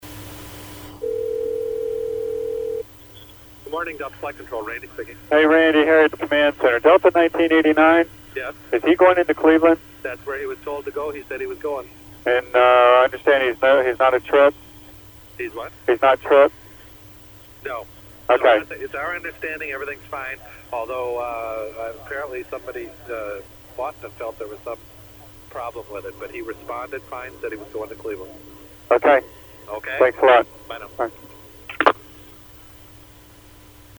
At 9:55, Herndon Center, Operations Position 27 called Delta asking about the flight.  Delta confirmed that it was landing normally at Cleveland.